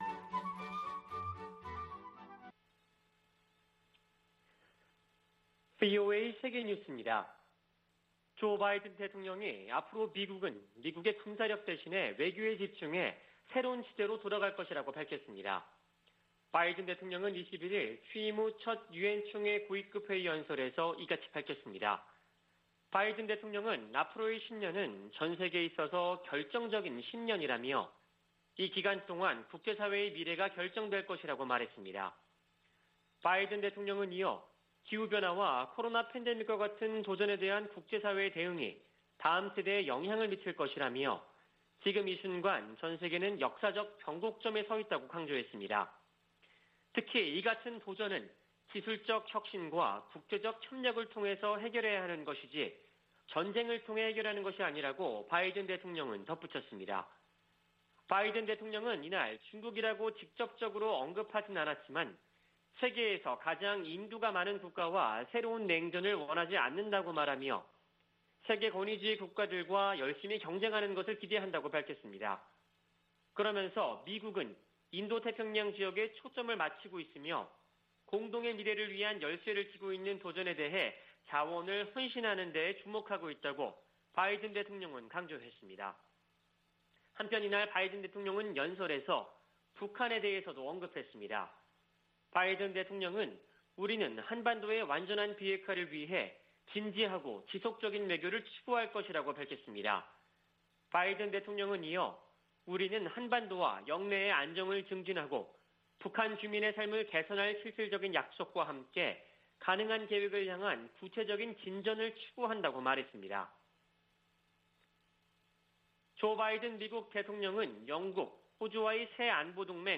VOA 한국어 아침 뉴스 프로그램 '워싱턴 뉴스 광장' 2021년 9월 22일 방송입니다. 북한이 우라늄 농축 등 핵 프로그램에 전력을 기울이고 있다고 국제원자력기구 사무총장이 밝혔습니다. 미 국무부는 북한의 최근 핵 관련 움직임과 탄도미사일 발사가 유엔 안보리 결의 위반이며 한국과 일본 등에 위협이라고 지적했습니다. 미 국방부는 한국보다 더 강력한 동맹국은 없다며, 한반도 위협에 군사적 대비태세를 갖추고 있다고 강조했습니다.